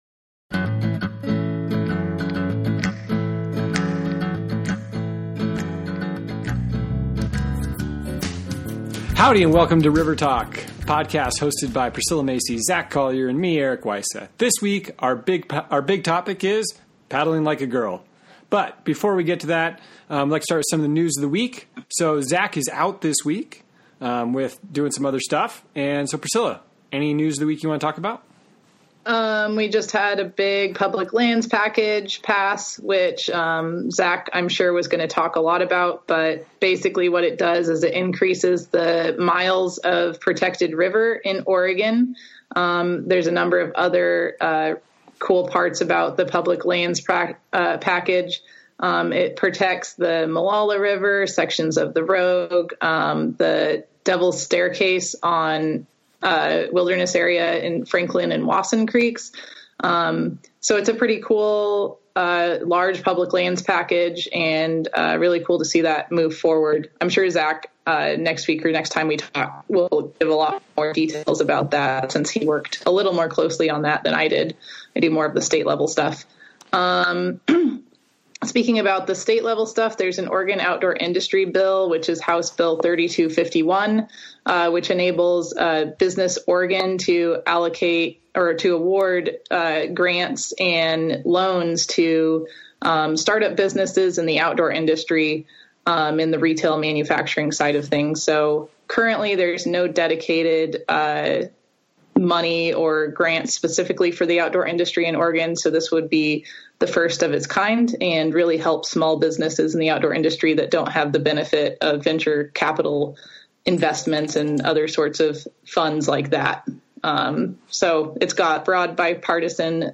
Gear Garage Live Show No. 43: Paddle Like A Girl, Man! Interview